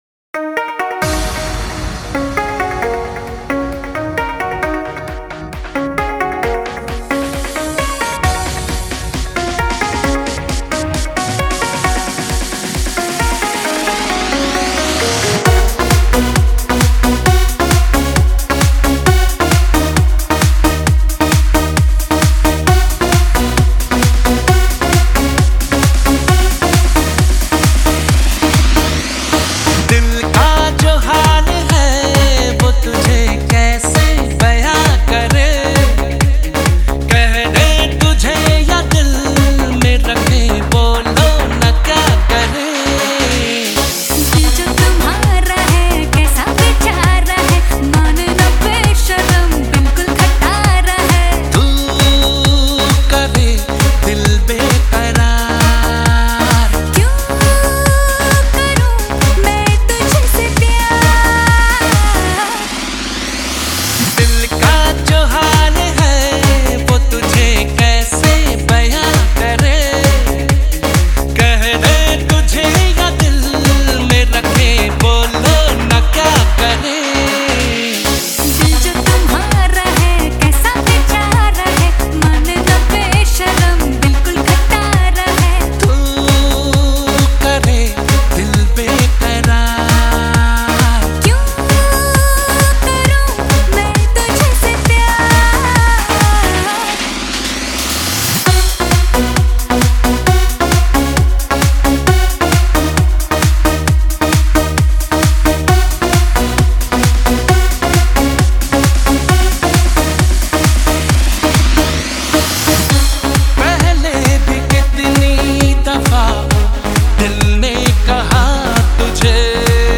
Bollywood Mp3 Music 2013